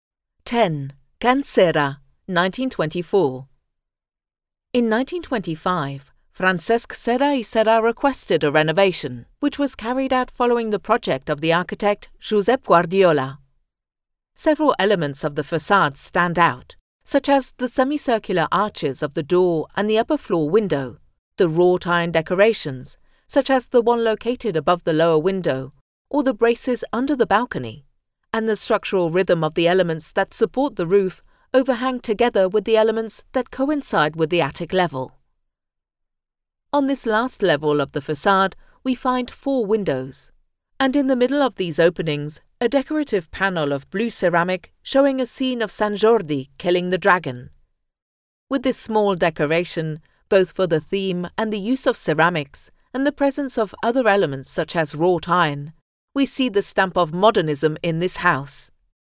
Ruta Modernista audioguiada